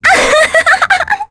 Mirianne-vox-Happy3.wav